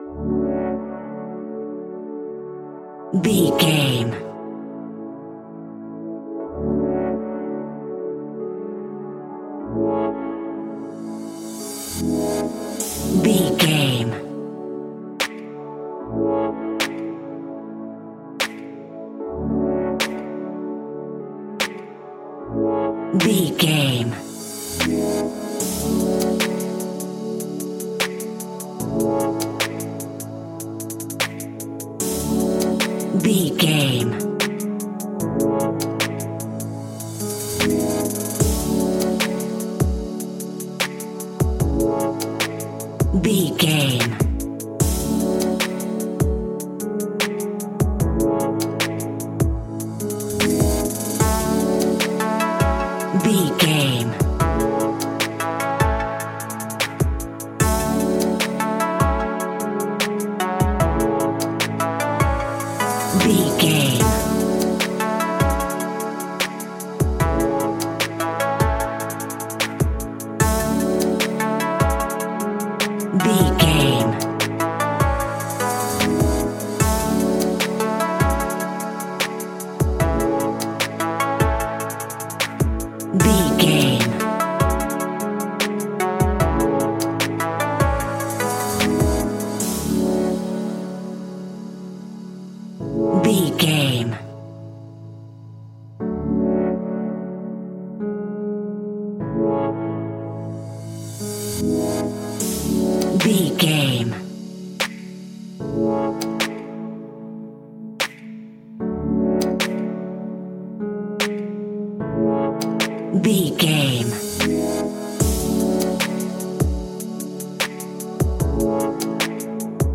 Ionian/Major
hip hop
instrumentals
chilled
laid back
groove
hip hop drums
hip hop synths
piano
hip hop pads